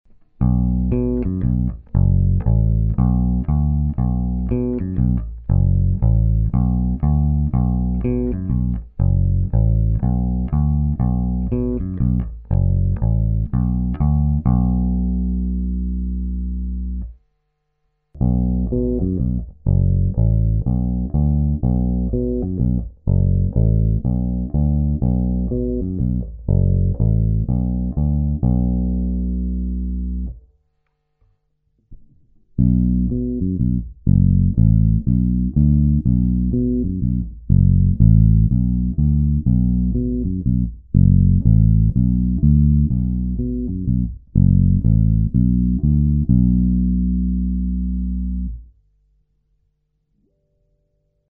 Nahrate piamo do zvukovky a bez uprav smile
1.) Oba snimace
I. clony naplno
II. stiahnuta krkova clona
III. stiahnuta kobylkova clona